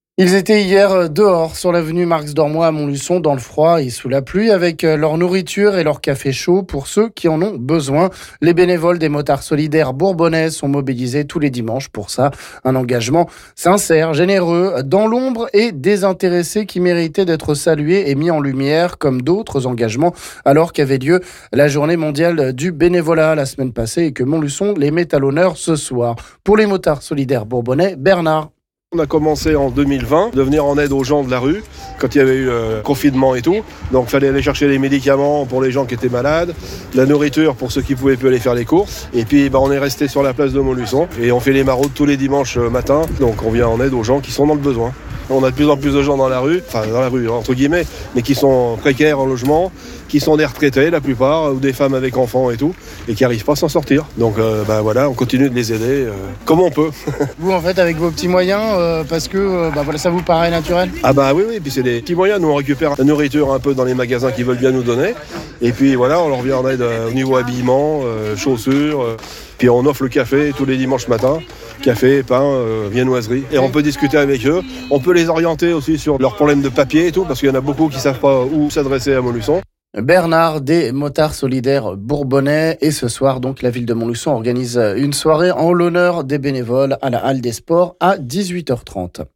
A l'occasion de la journée mondiale du bénévolat, micro tendu à un motard solidaire bourbonnais engagé pour les plus démunis à Montluçon